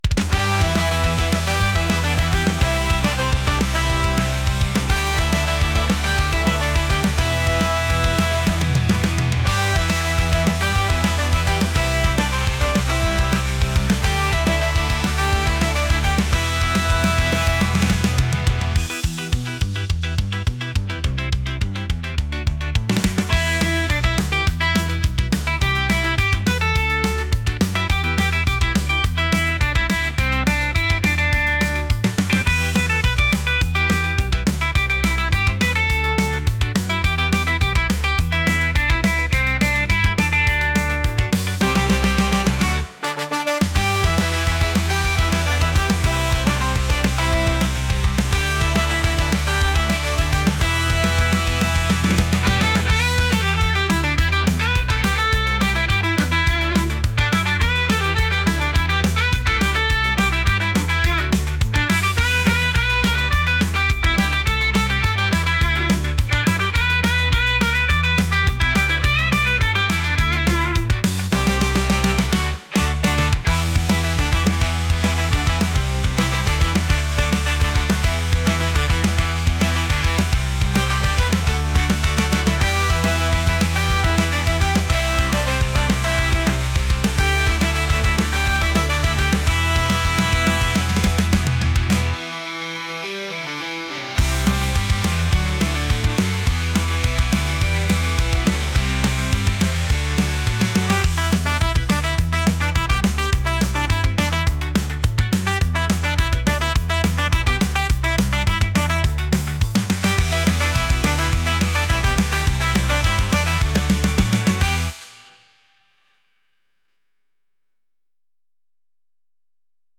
ska | punk | energetic